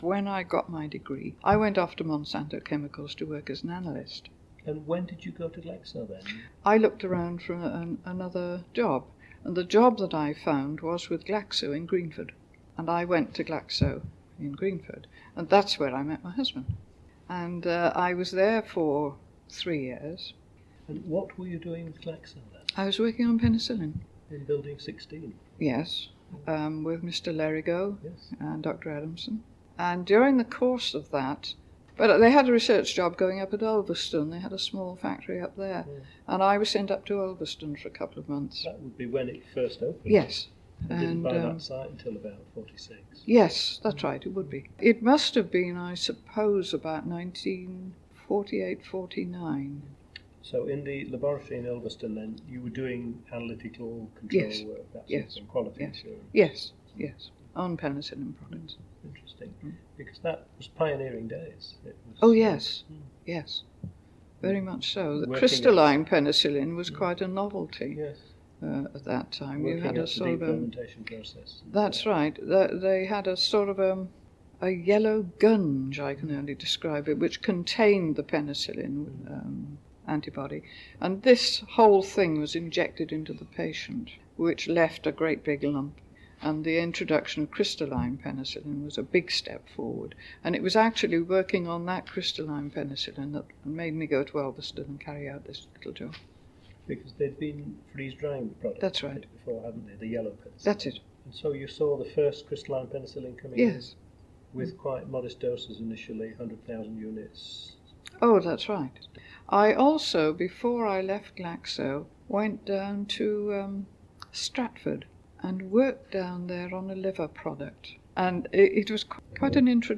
RCPharms Museum has a growing collection of oral history recordings where pharmacists past and present share their experiences in their own words.